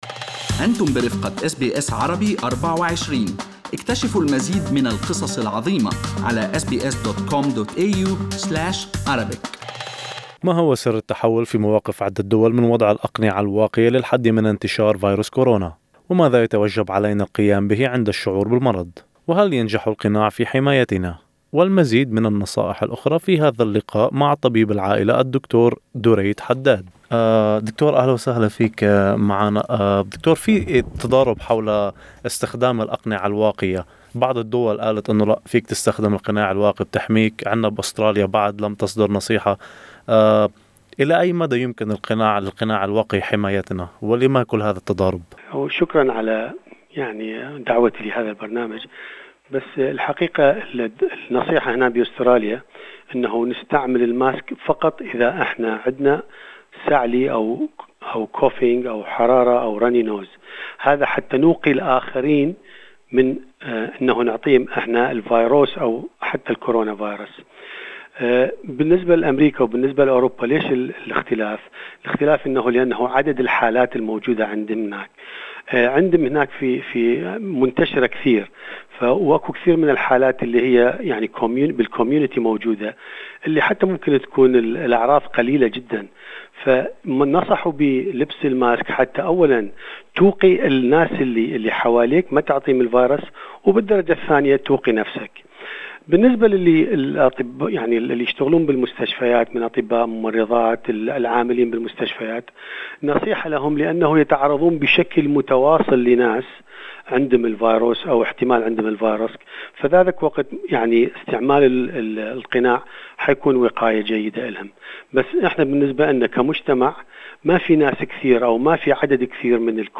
في لقاء مع طبيب ..ارتداء القناع للحماية من كورونا بين الخرافة والحقيقة